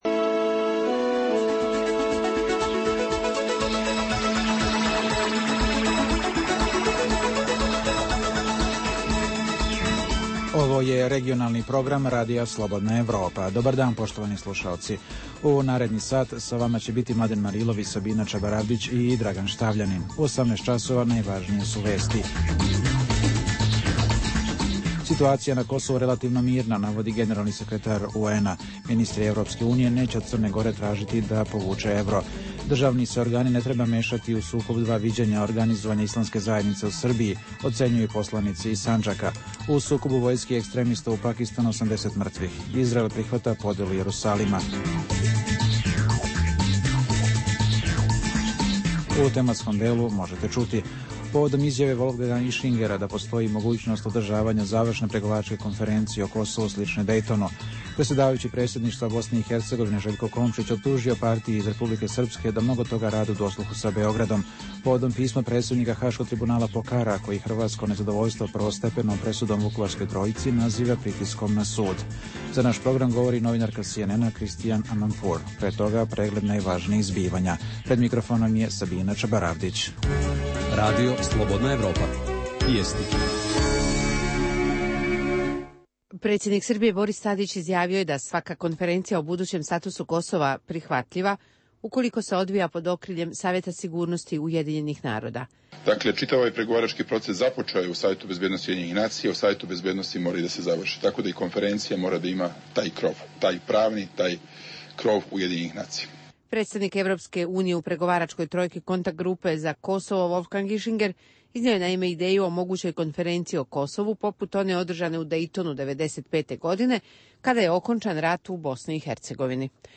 Emisija o dešavanjima u regionu (BiH, Srbija, Kosovo, Crna Gora, Hrvatska) i svijetu. Prvih pola sata emisije sadrži regionalne i vijesti iz svijeta, te najaktuelnije i najzanimljivije teme o dešavanjima u zemljama regiona i teme iz svijeta. Preostalih pola sata emisije, nazvanih “Dokumenti dana” sadrži analitičke teme, intervjue i priče iz života.